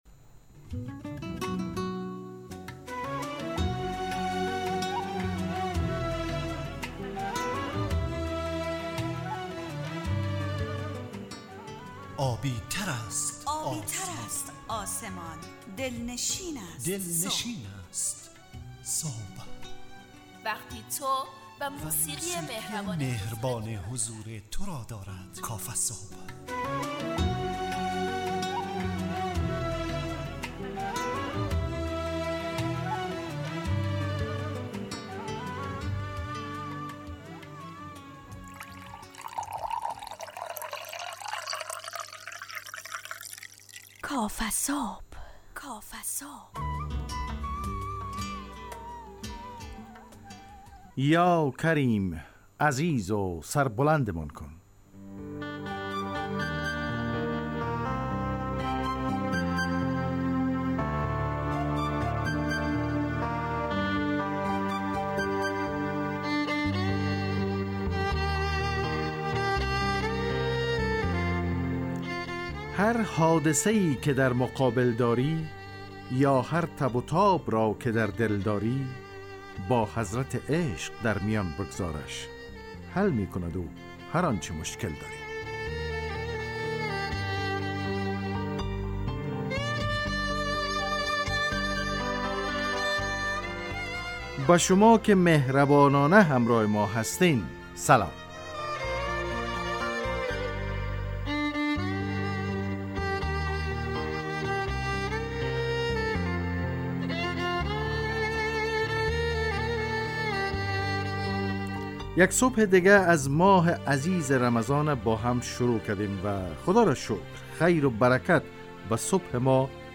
کافه‌صبح – مجله‌ی صبحگاهی رادیو دری با هدف ایجاد فضای شاد و پرنشاط صبحگاهی